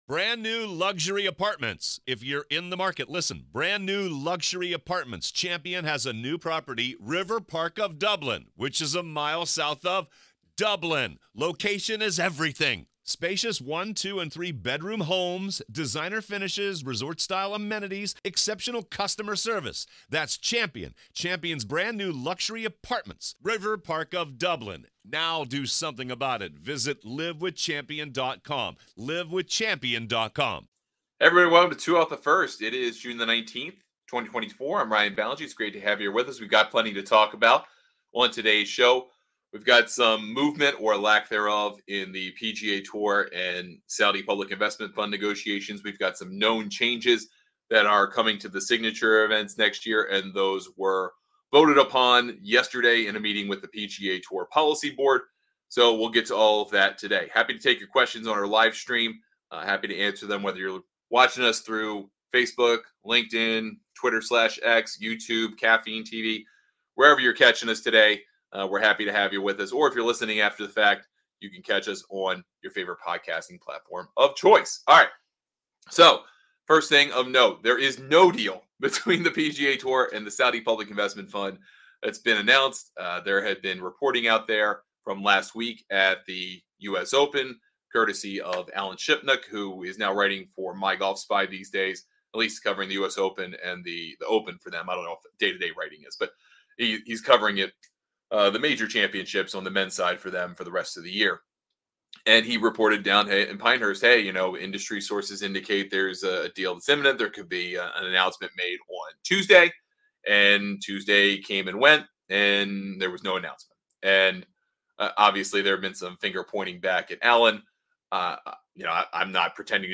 On today's live show